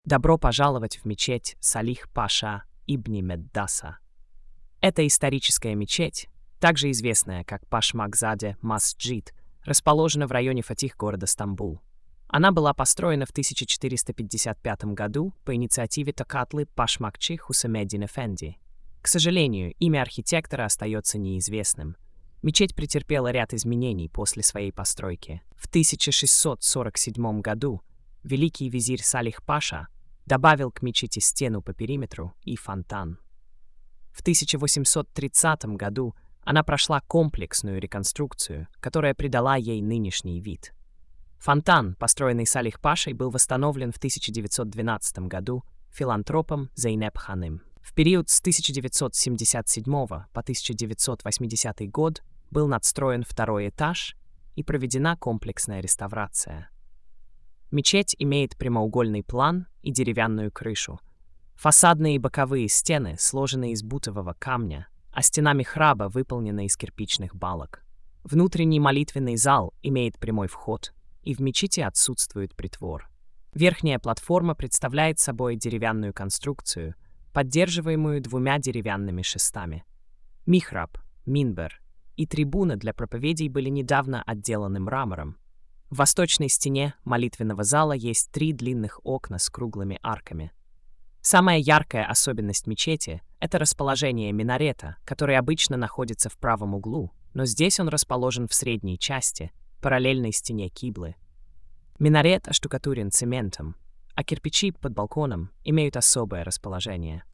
Аудиоповествование: